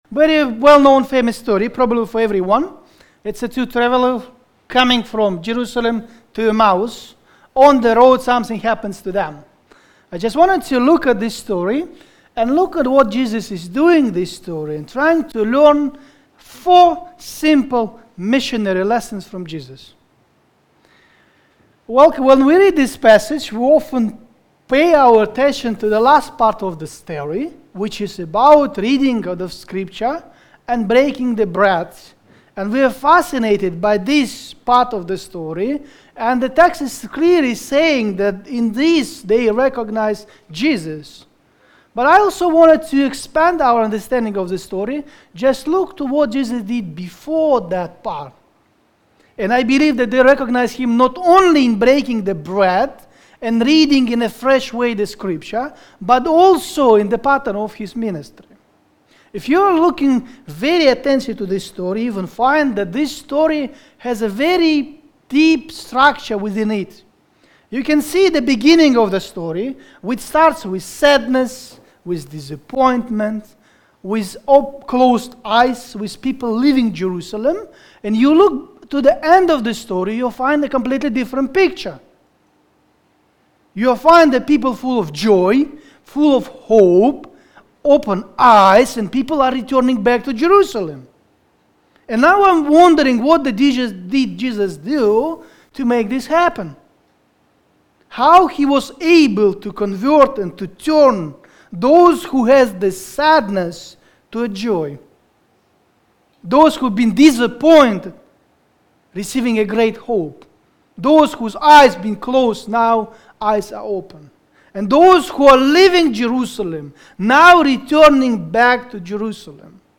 Preacher: Guest Speaker
Topical Sermon Passage: Luke 24:13-35 Service Type: Sunday Morning